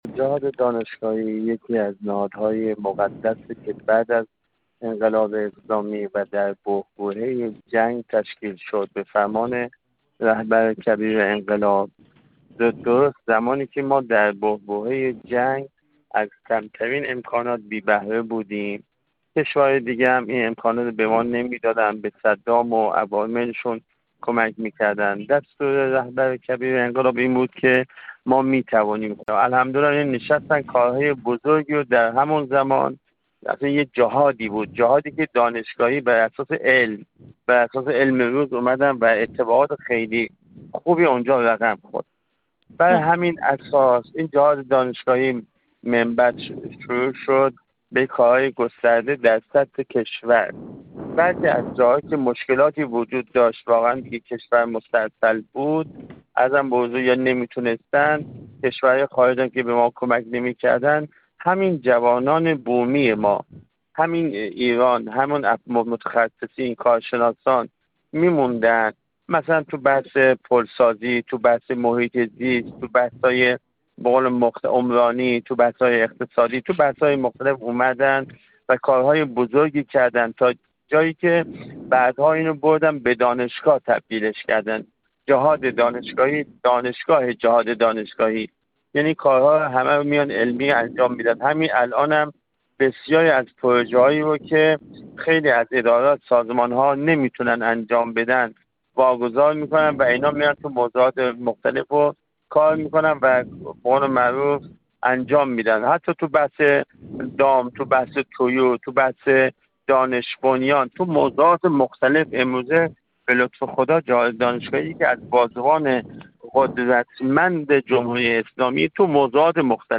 محمدرضا احمدی، دبیر دوم کمیسیون آموزش و تحقیقات مجلس
محمدرضا احمدی، دبیر دوم کمیسیون آموزش و تحقیقات مجلس شورای اسلامی در گفت‌وگو با ایکنا درباره تأثیر اقدامات و فعالیت‌های علمی و فناورانه جهاددانشگاهی در تزریق «روحیه خودباوری» و «ما می‌توانیم»، گفت: جهاددانشگاهی یکی از نهادهای مقدسی است که پس از پیروزی انقلاب اسلامی و در بحبوحه جنگ، هنگامی که از کمترین امکانات بی‌بهره بودیم، به فرمان رهبر کبیر انقلاب اسلامی تشکیل شد.